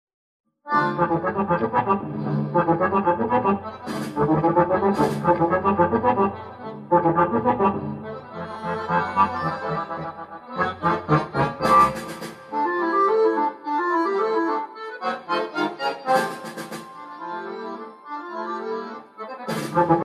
Sinfonietta per orchestra di fisarmoniche in 3 movimenti